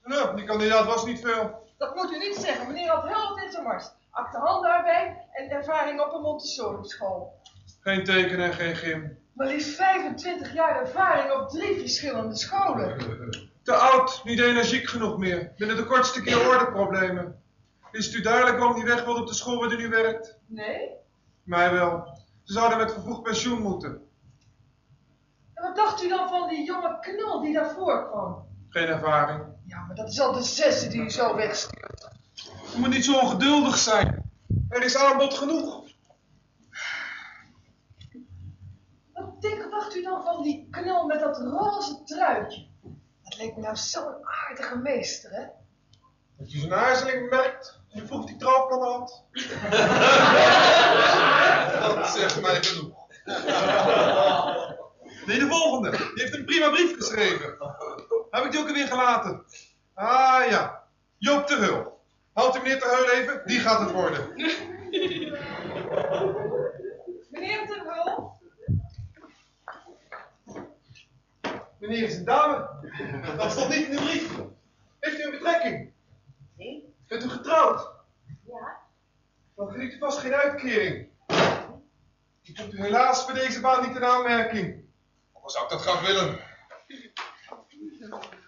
Opname tijdens een Try Out met een cassetterecorder, matige kwaliteit.